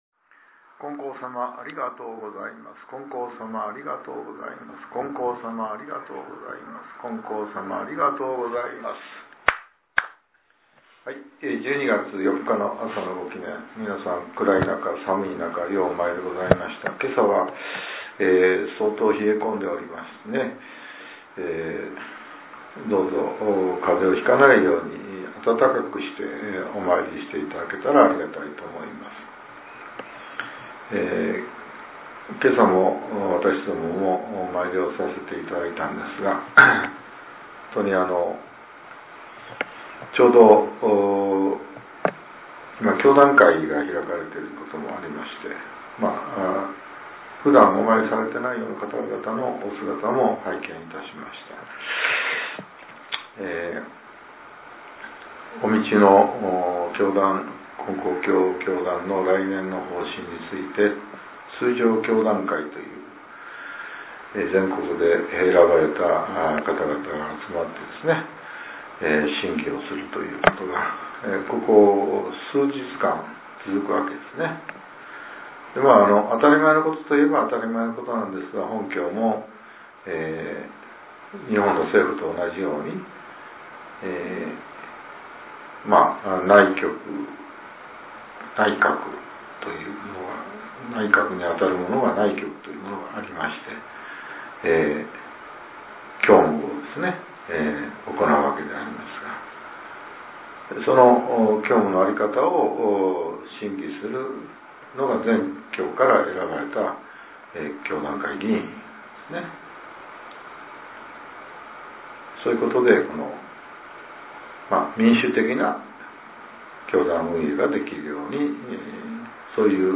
令和７年１２月４日（朝）のお話が、音声ブログとして更新させれています。